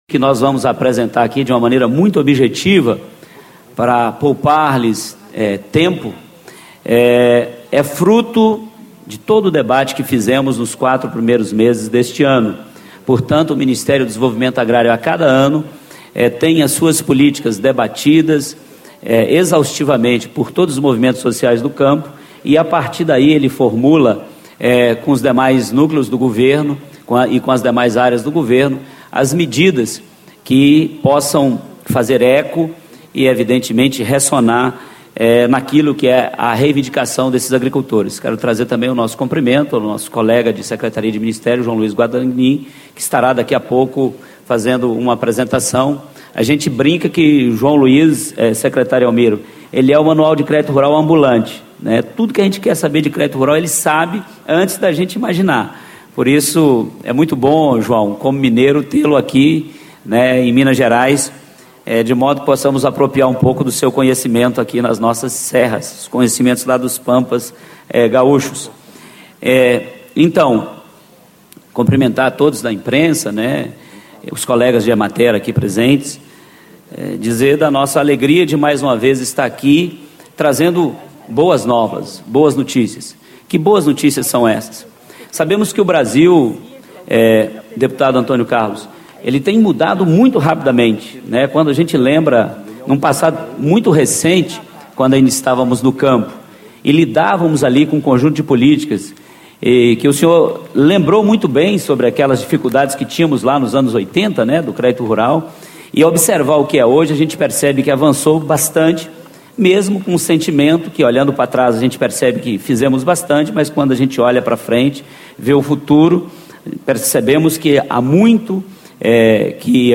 Argileu Martins da Silva, Diretor do Departamento de Assistência Técnica e Extensão Rural da Secretaria da Agricultura Familiar do Ministério do Desenvolvimento Agrário
Discursos e Palestras